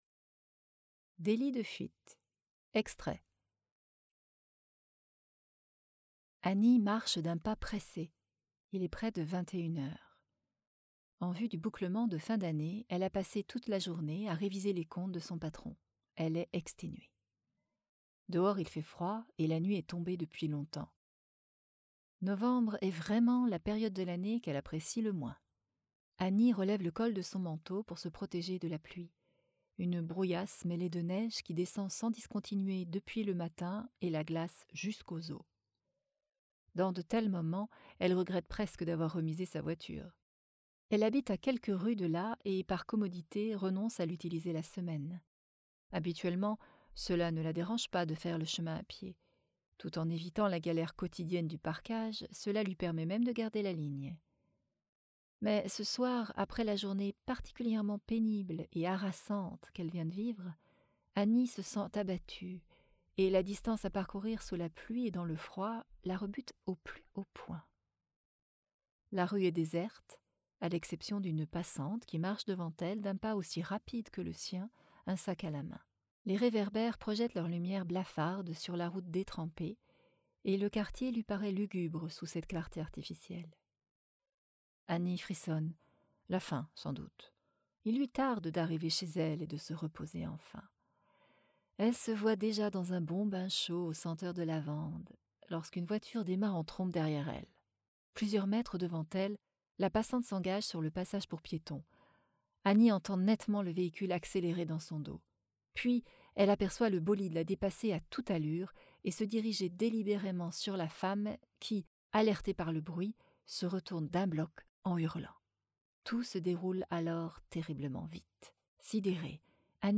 Extraits voix off.